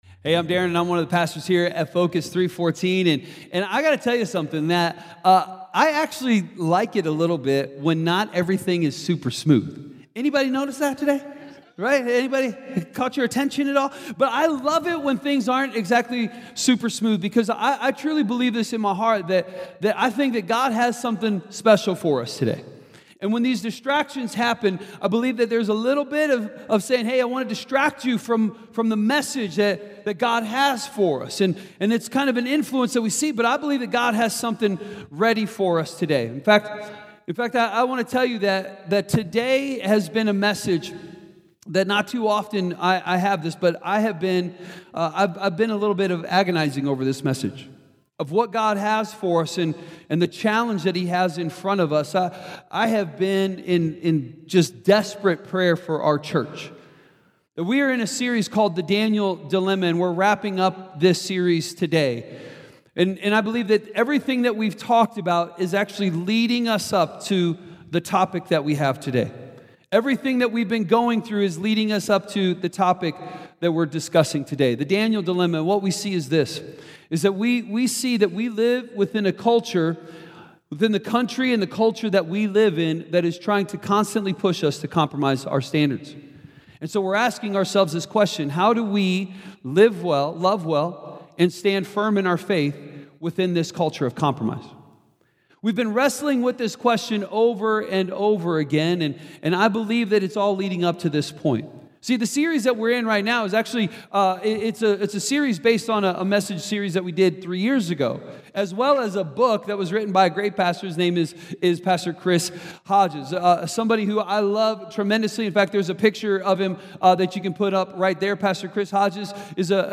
A message from the series "The Daniel Dilemma." How do you hold on to God's truth in a culture of compromise?